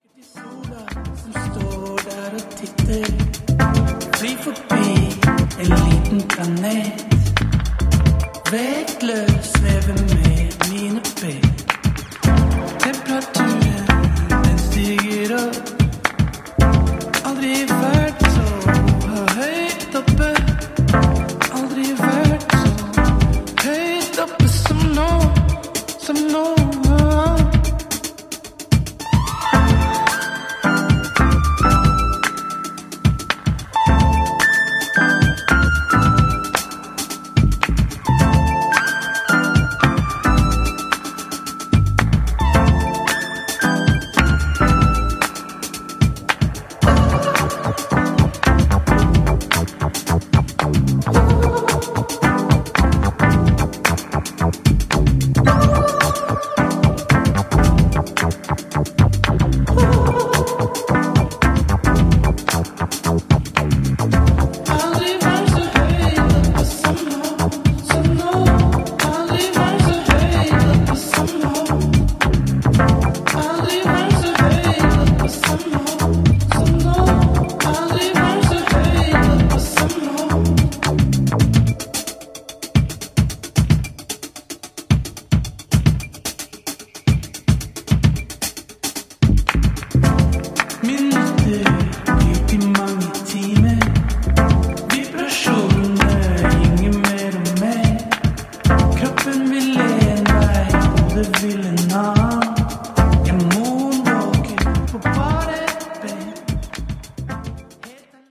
warm, lovely space anthem